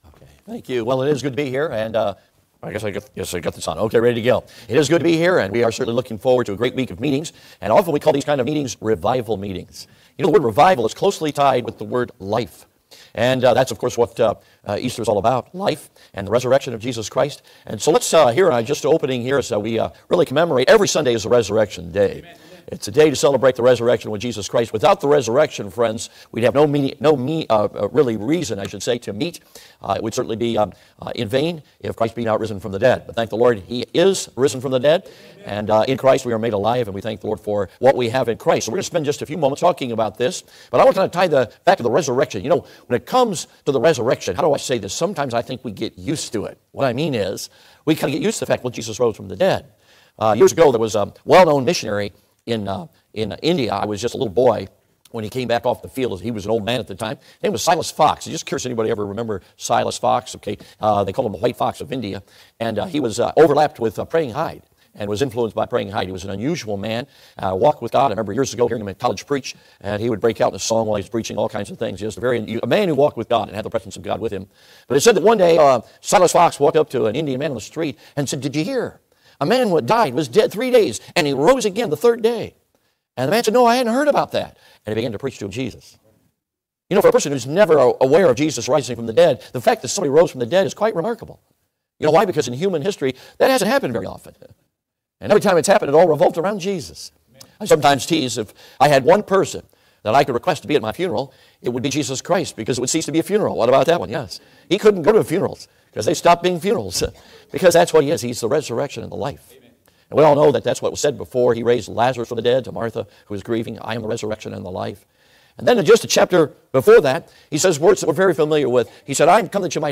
Passage: John 12:23-25 Service Type: Adult Sunday School Class « Live a Life That’s Worth Living Are You Ready to Die?